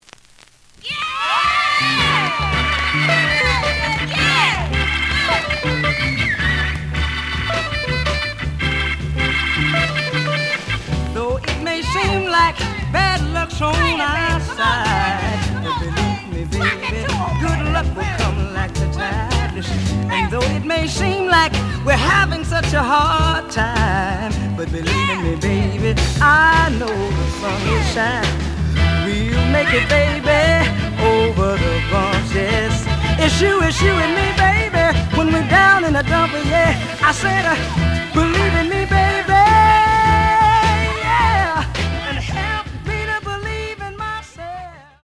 試聴は実際のレコードから録音しています。
●Genre: Soul, 60's Soul
●Record Grading: VG- (両面のラベルにダメージ。両面の盤に曇り。)